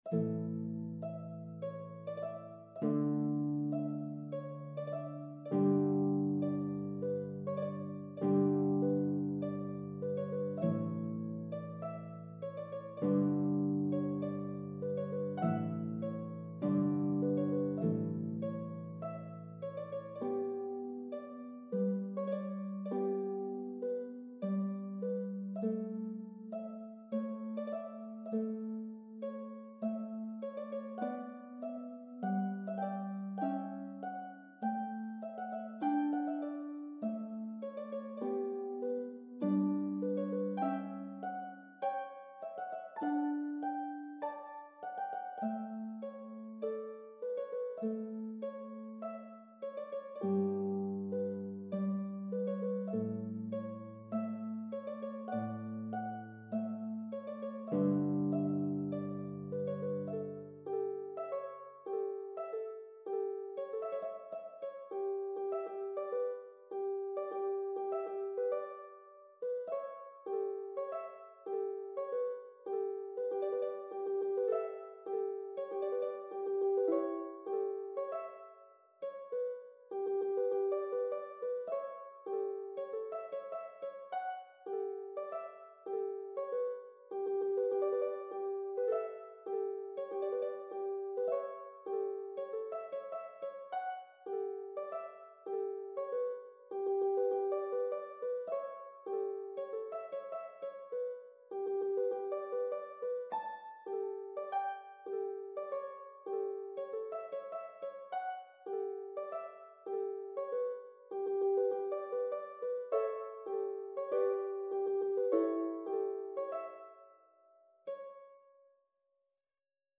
is a piobaireachd